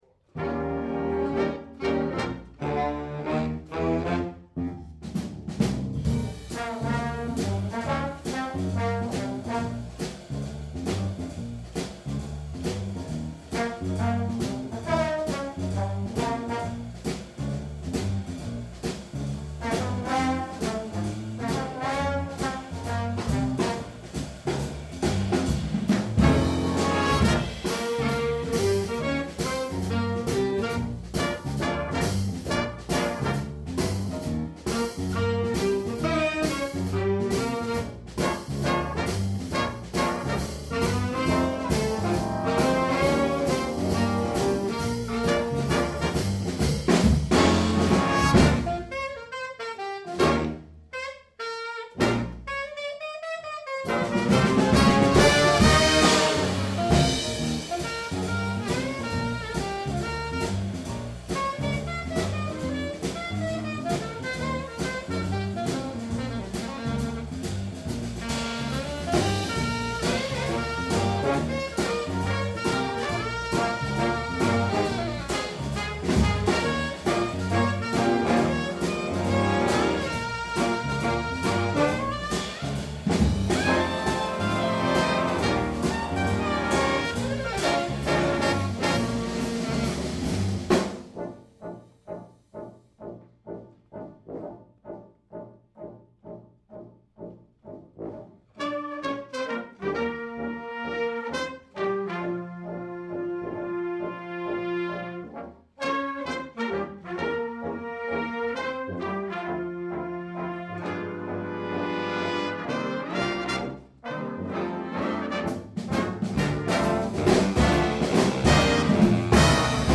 Instrumentation: Big Band (8 brass) + Opt. Tuba, Opt. Guitar